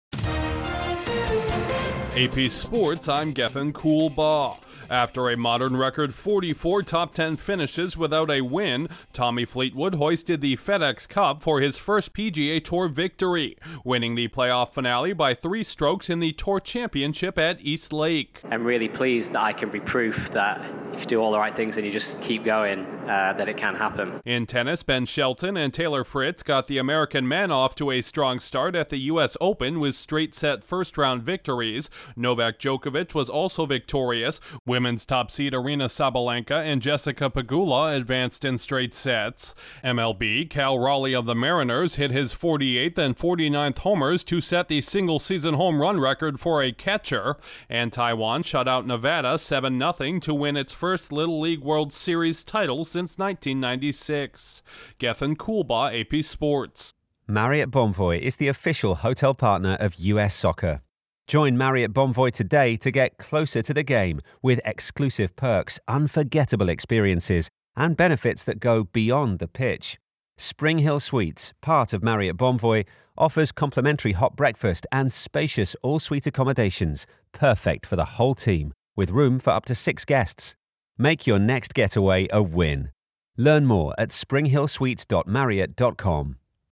Correspondent